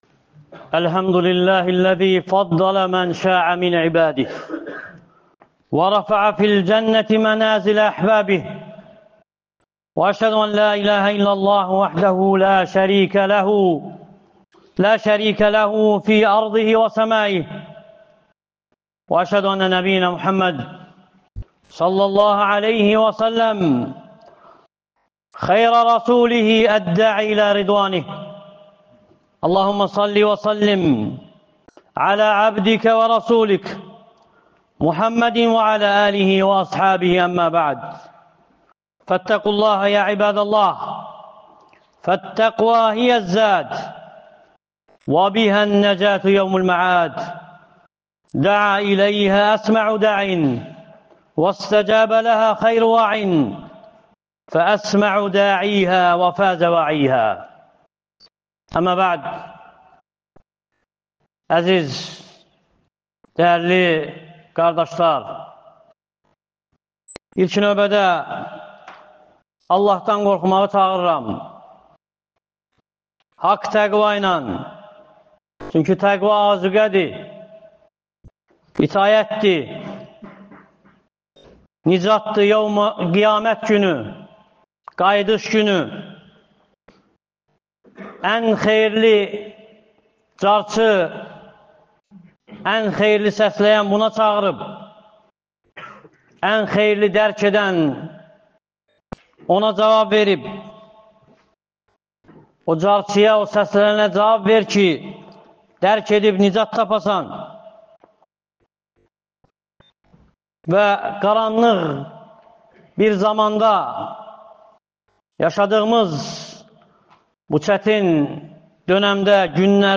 Əbu Bəkr əs-Siddiqin (radiyallahu anhu) fəziləti (Cümə xütbəsi — 20.12.2024) | Əbu Bəkr məscidi